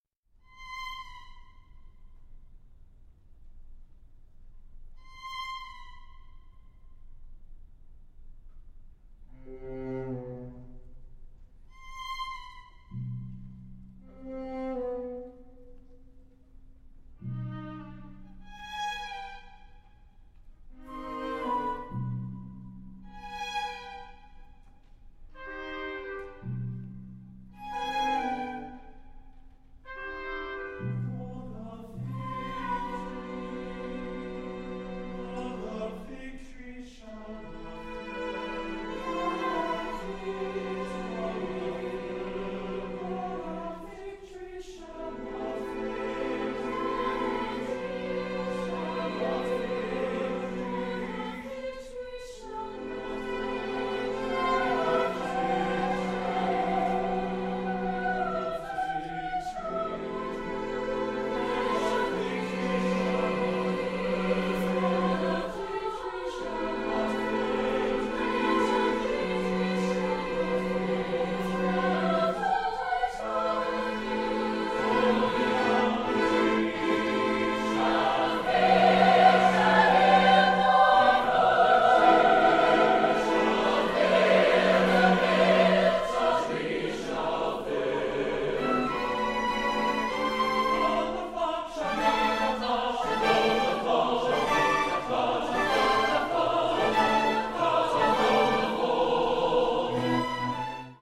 (SSATB with chamber orchestra)